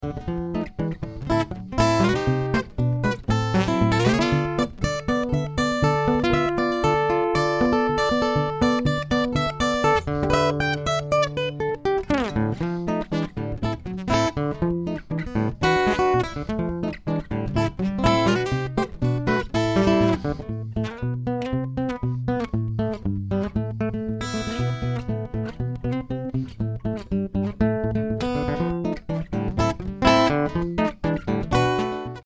Ashington Folk Club - Singers, Musicians & Poets 01 June 2006